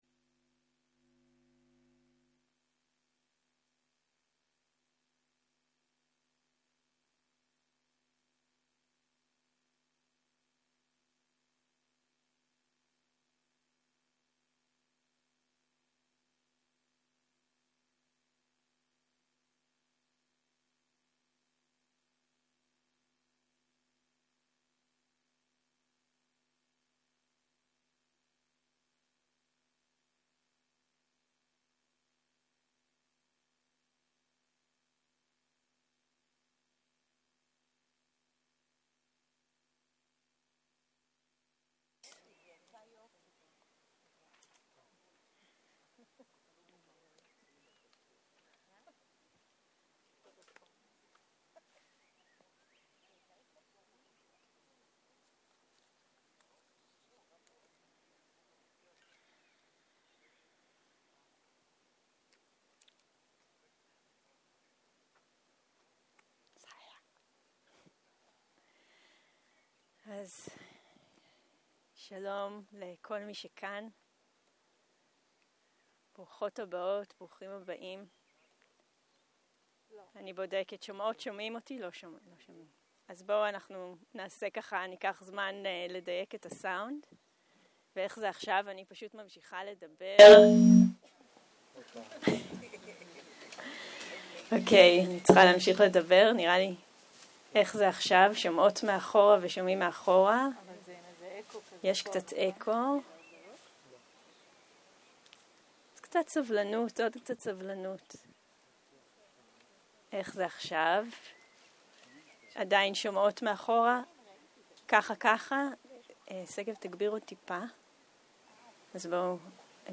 סוג ההקלטה: שיחת פתיחה